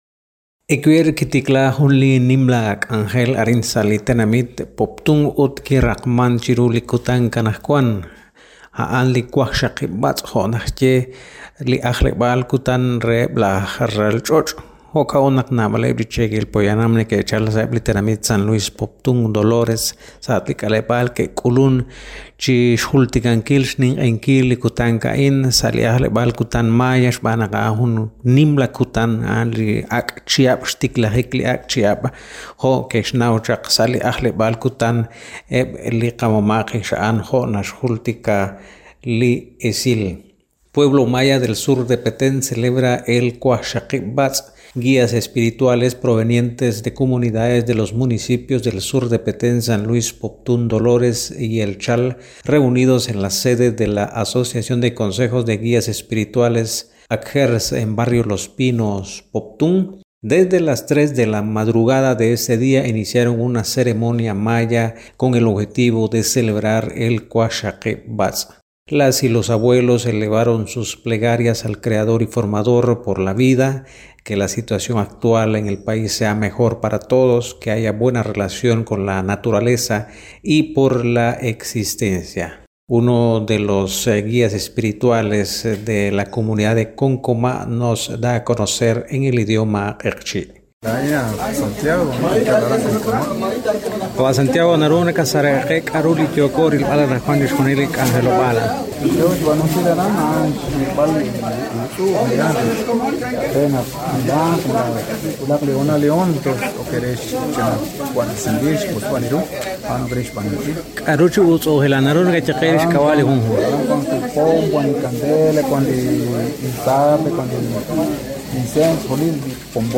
Sitio de noticias de Guatemala